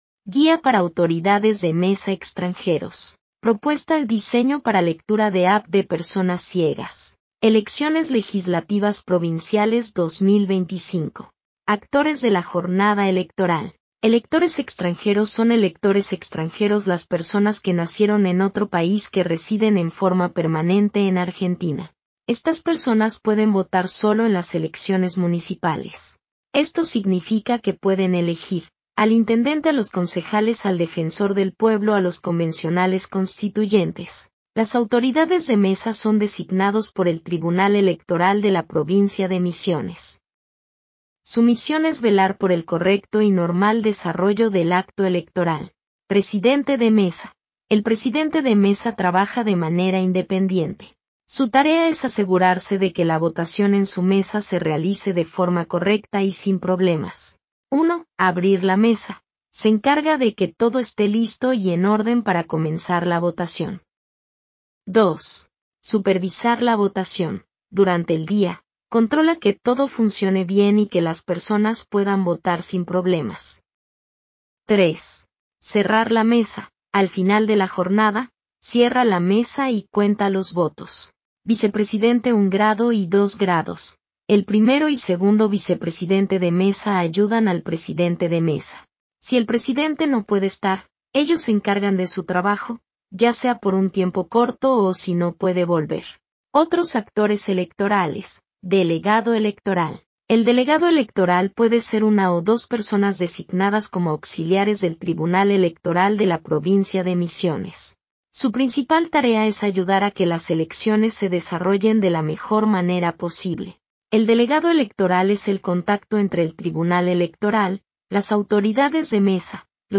Instructivo audible para autoridades de mesa extranjeros para personas con discapacidad visual.
(Audio realizado por la Biblioteca Pública de las Misiones)